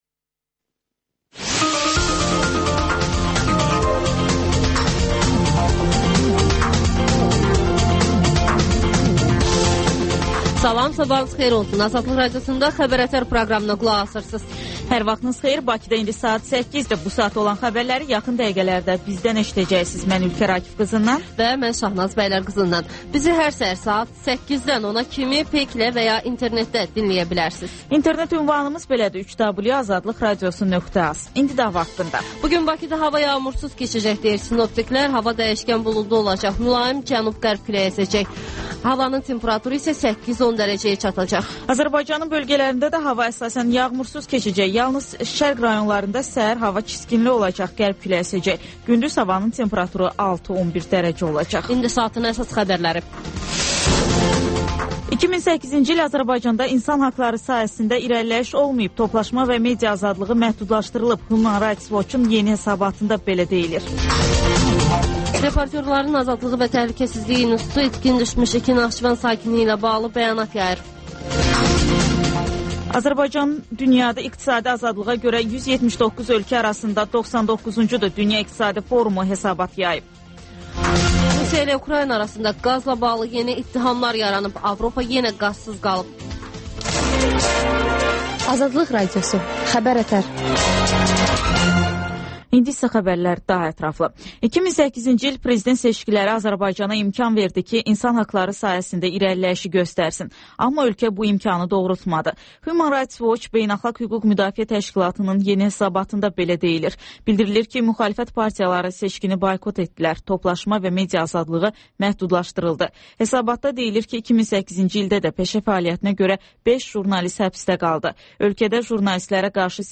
Xəbərlər, müsahibələr